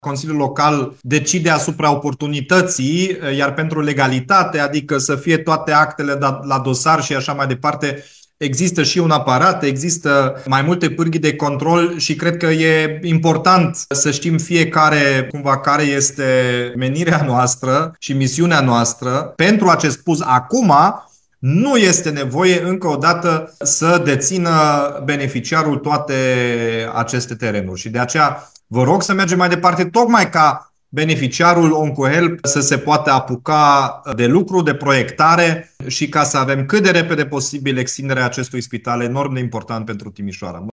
Primarul Dominic Fritz a declarat că situația juridică a terenurilor care fac obiectul PUZ-ului de la OncoHelp va fi verificată în amănunt cu ocazia emiterii autorizației de construcție.